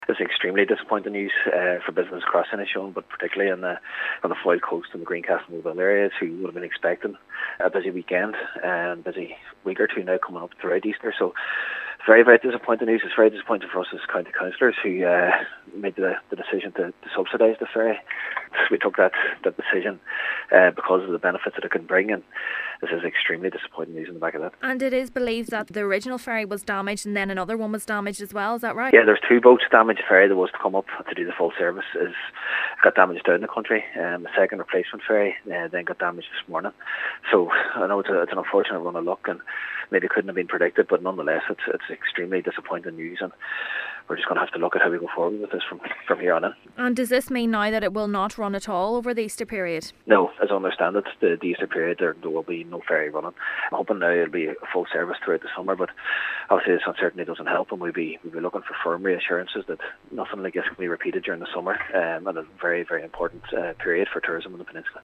Cathaoirleach of the Inishowen Municipal District Councillor Jack Murray says this is extremely disappointing news: